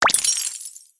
gem_collect_01.mp3